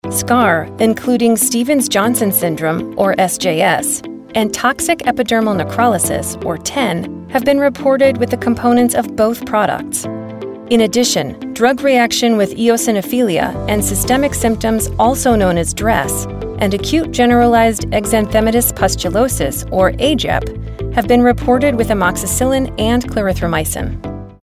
Medical
authoritative, informative, Matter of Fact, Medical, neutral, Straight Forward